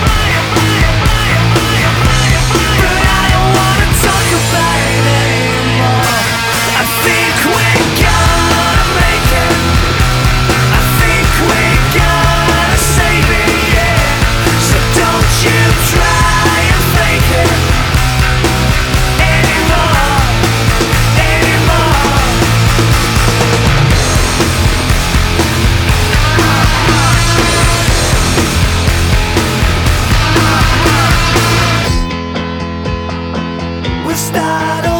1996-05-15 Жанр: Рок Длительность